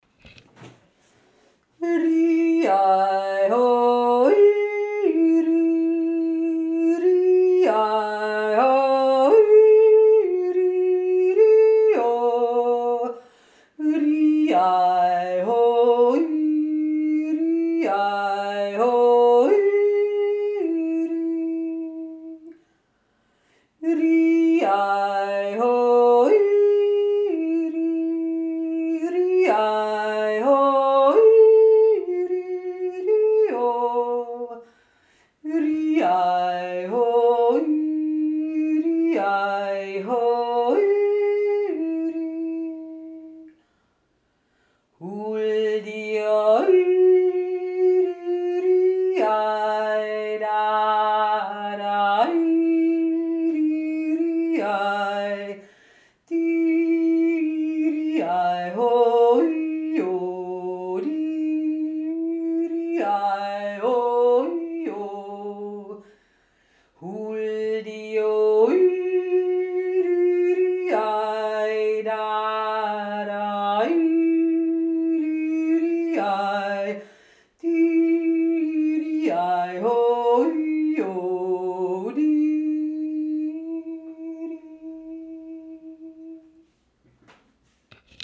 INNSBRUCK jodelt
1. Stimme